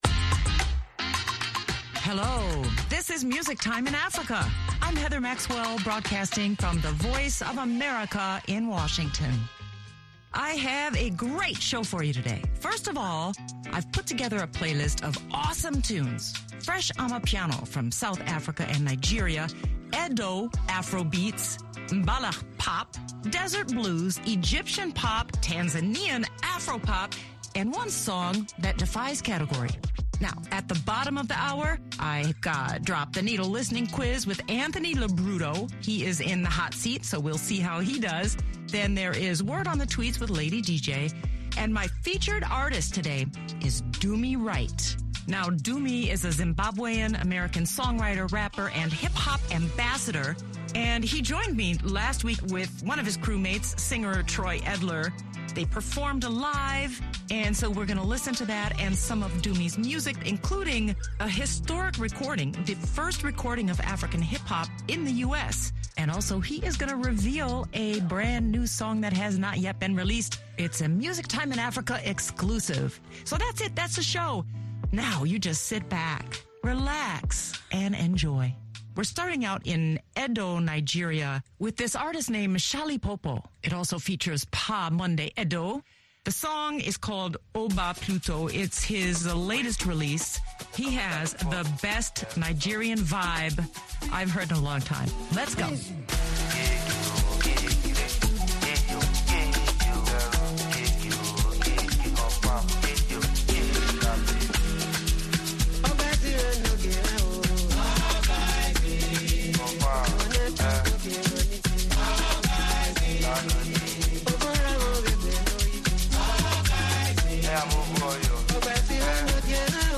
The playlist features fresh amapiano from South Africa and Nigeria, Edo afrobeats, Mbalax pop, desert blues, Egyptian pop, Tanzanian Afropop and one song that defies category.